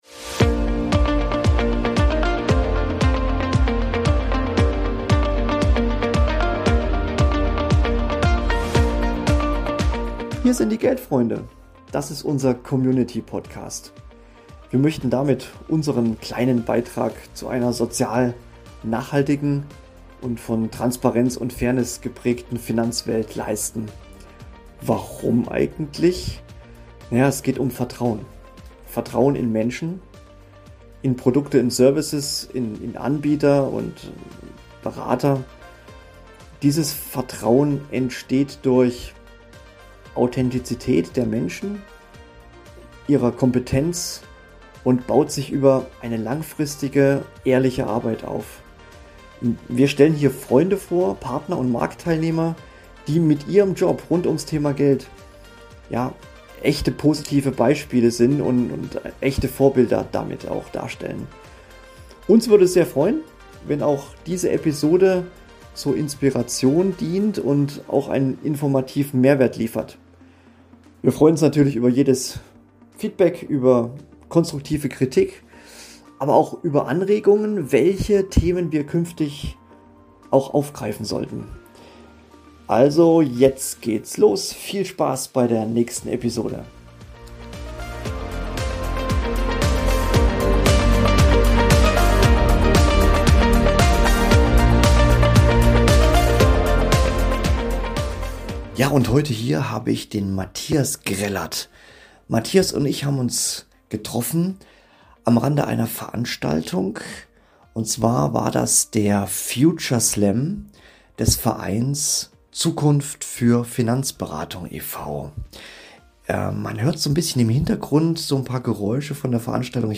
auf dem Future Slam des Vereins Zukunft für Finanzberatung e.V.